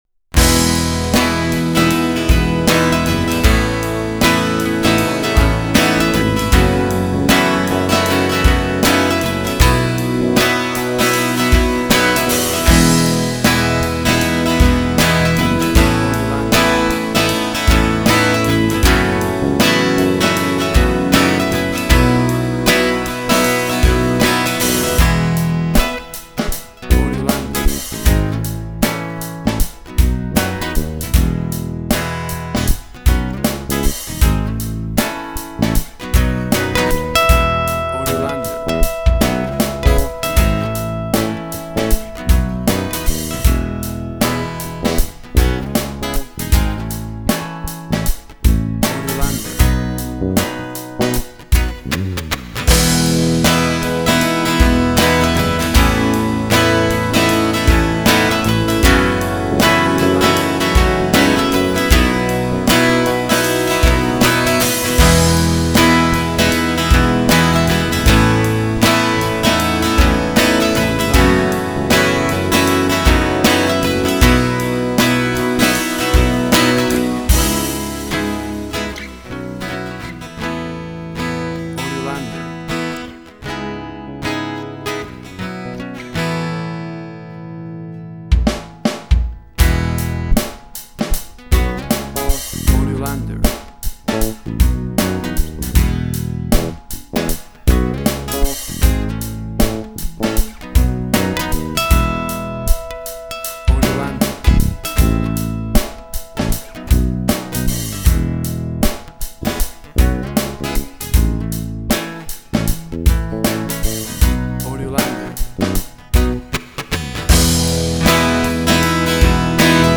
Big cheesy chorus, and then a dynamic build from the verse.
WAV Sample Rate: 16-Bit stereo, 44.1 kHz
Tempo (BPM): 77